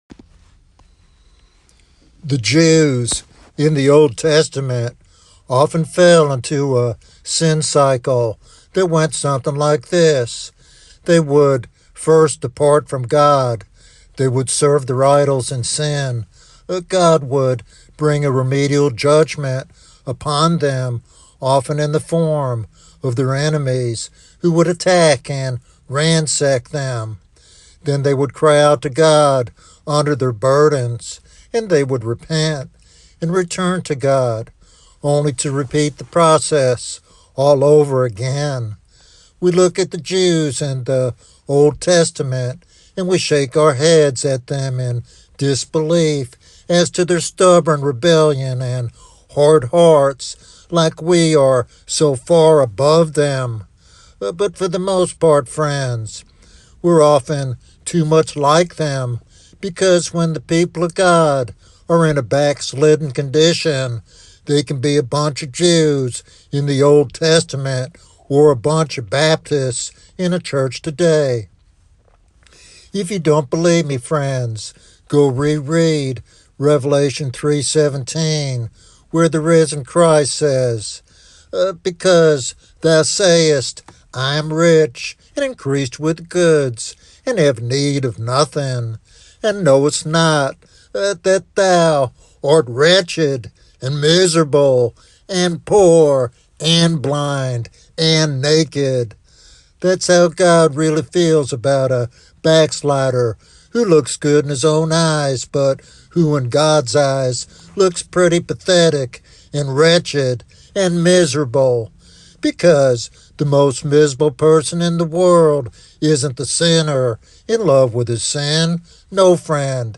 This sermon encourages believers to understand their condition and seek restoration through repentance and faith.